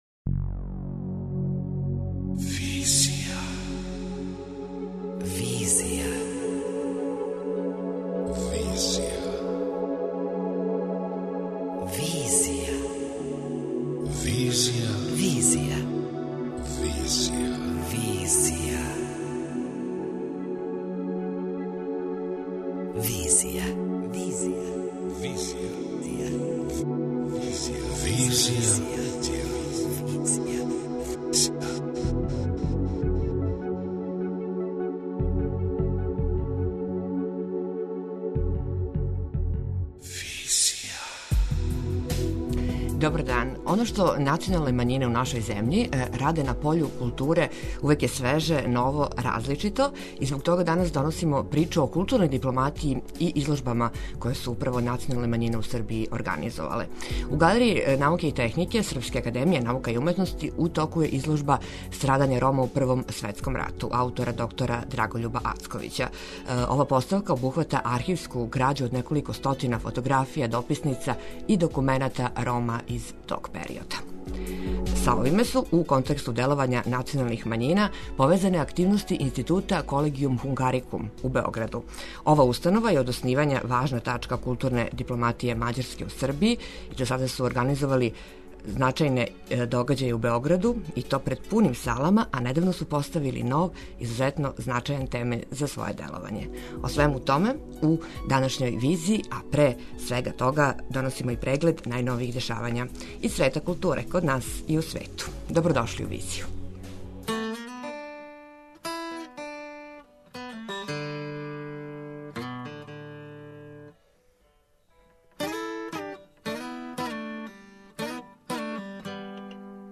преузми : 26.76 MB Визија Autor: Београд 202 Социо-културолошки магазин, који прати савремене друштвене феномене.